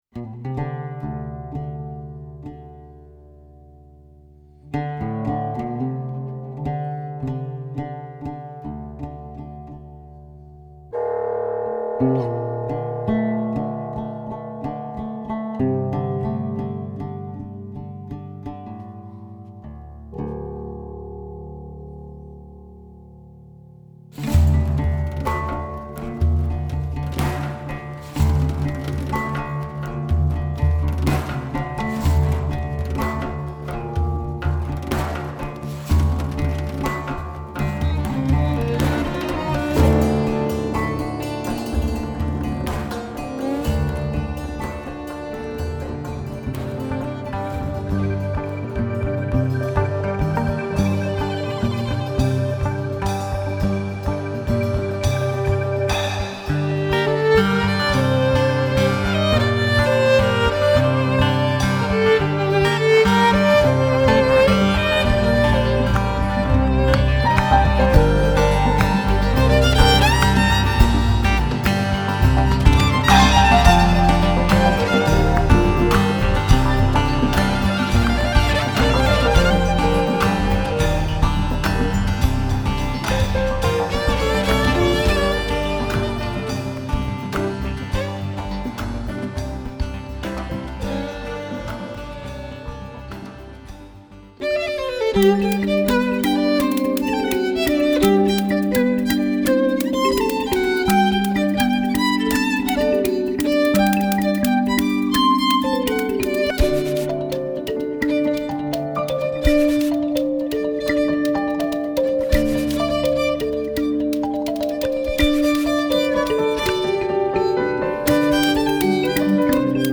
zarb, santour, percussions
zarb, saz, percussions
violon
piano, claviers